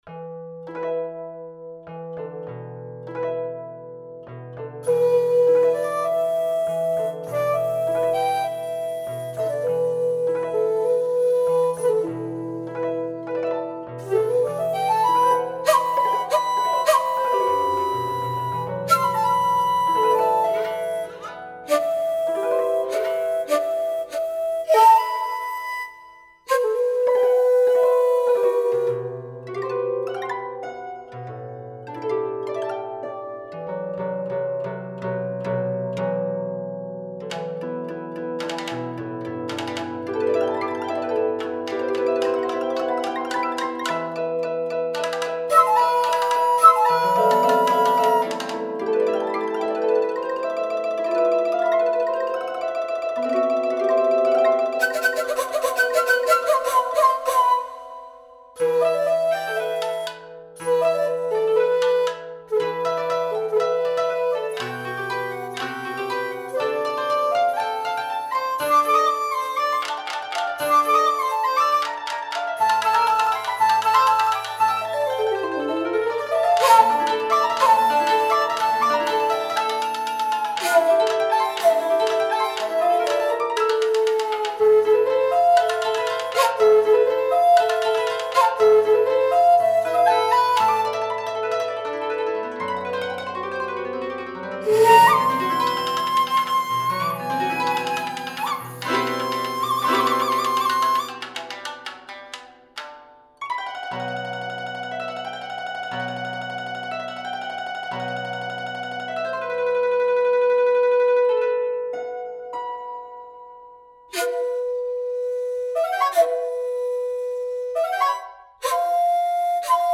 Op.8 Nr.1 my new piece in Japanese style
Shakuhachi: Japanese bamboo flute
Shamisen: a kind of plucked string instrument with 3 strings Koto